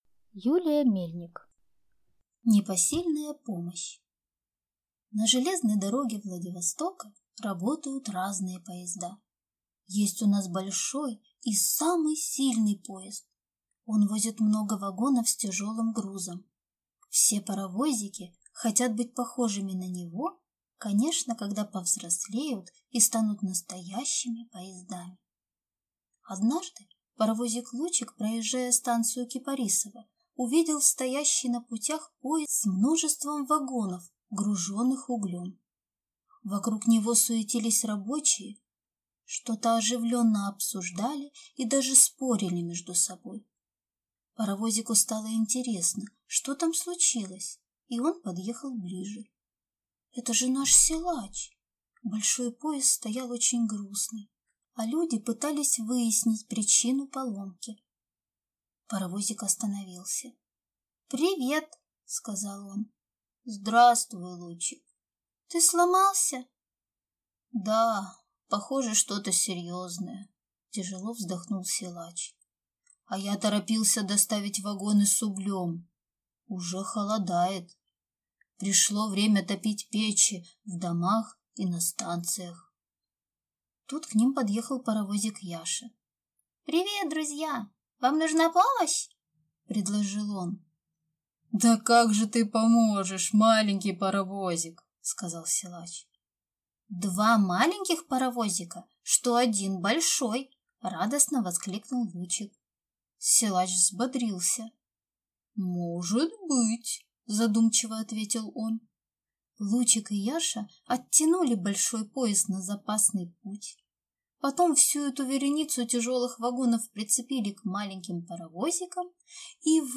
Аудиокнига Невероятные приключения паровозиков. Сборник 2 | Библиотека аудиокниг